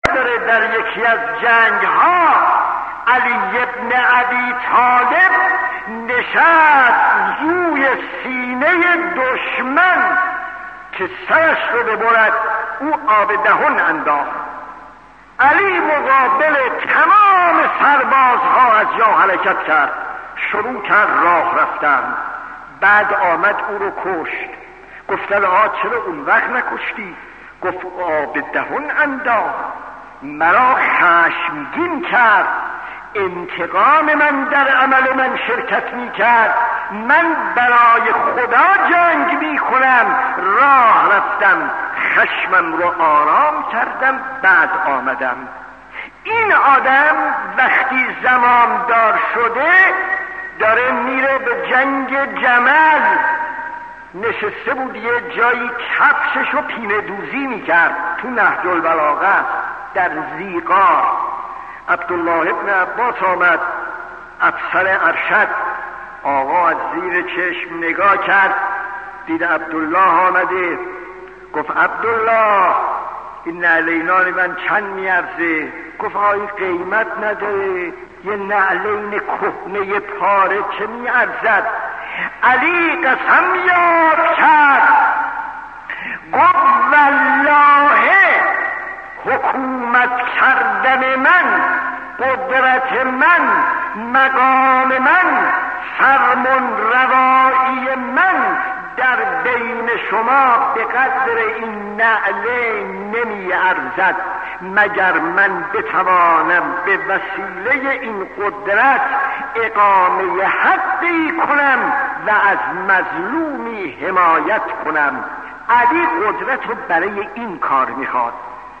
داستان 11 : نعلین امام علی خطیب: استاد فلسفی مدت زمان: 00:01:58